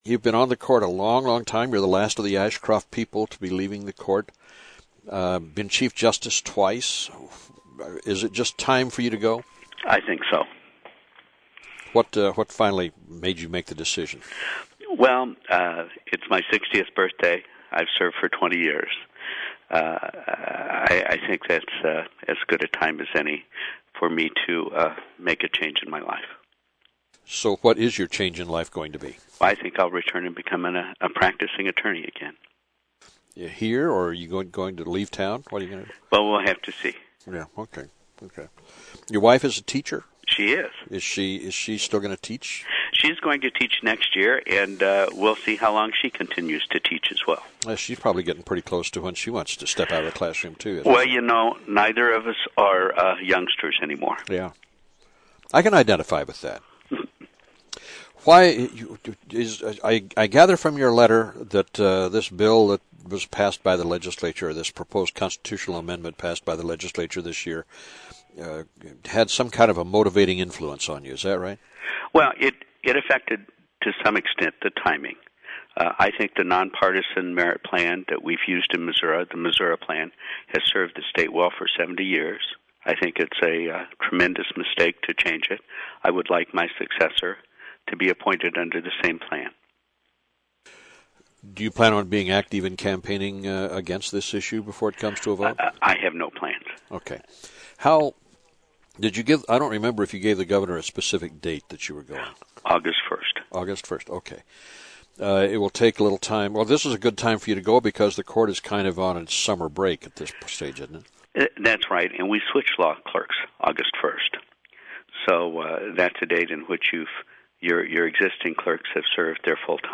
AUDIO: Price inerview 16:59 Share this: Facebook Twitter LinkedIn WhatsApp Email